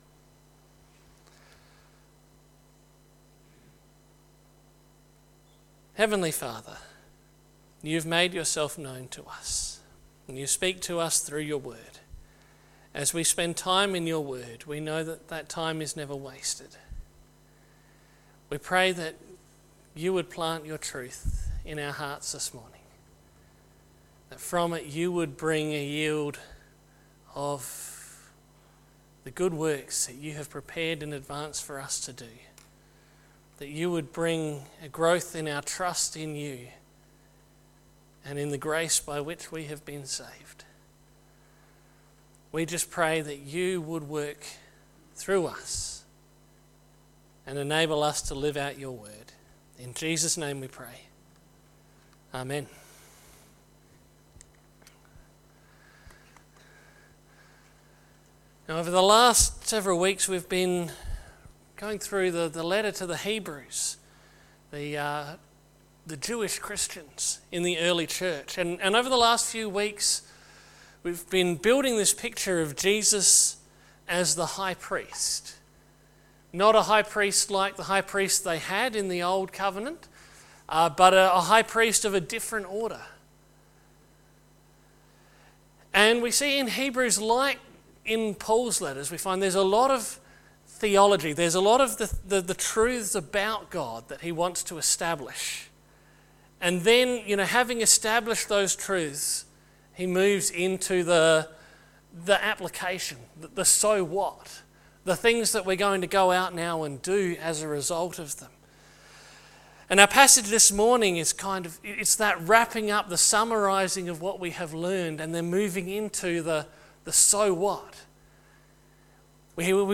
Sermons by Birdwood United Church